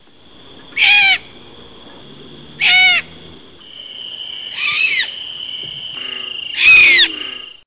Сипухой сову назвали за ее сиплый голос, больше похожий на хриплый кашель. А еще сова может громко щелкать клювом, наводя ужас на людей, решивших отдохнуть в лесной тиши.
sipuha-tyto-alba.mp3